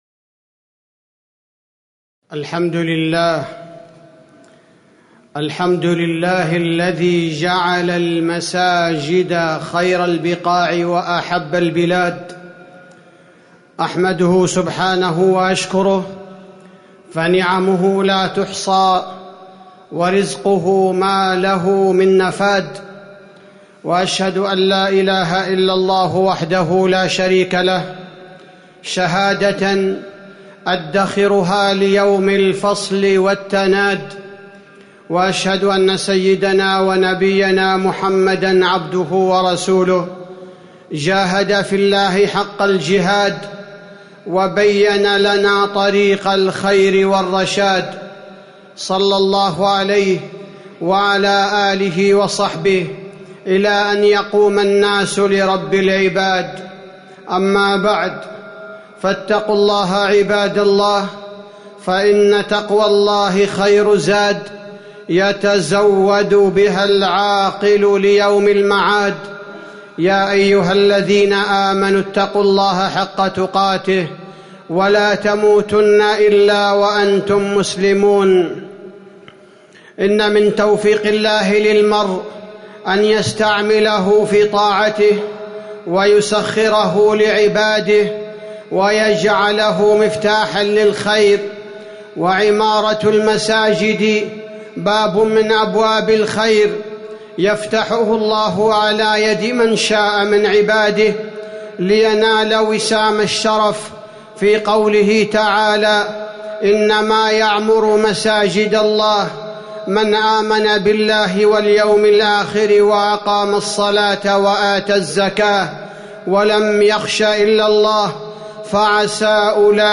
تاريخ النشر ١٠ جمادى الآخرة ١٤٤٠ هـ المكان: المسجد النبوي الشيخ: فضيلة الشيخ عبدالباري الثبيتي فضيلة الشيخ عبدالباري الثبيتي الإيمان بالله والتوكل عليه